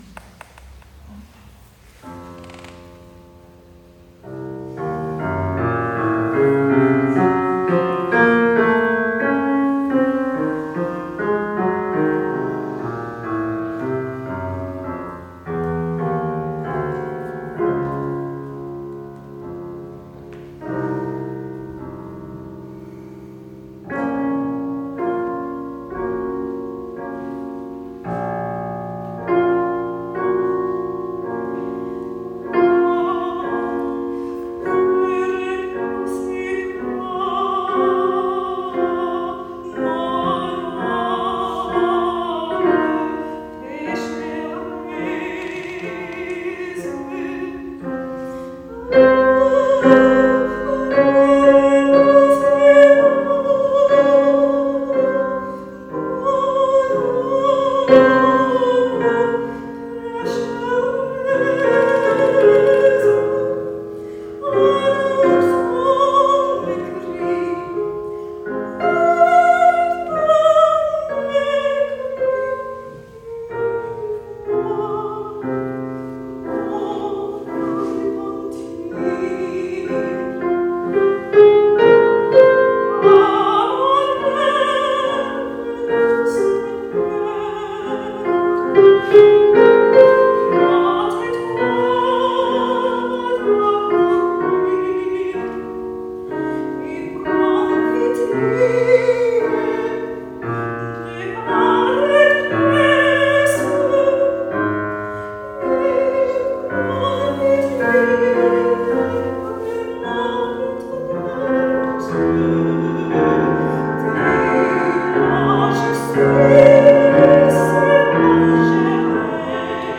live in concert, selected arias
soprano
piano - live in concert 2023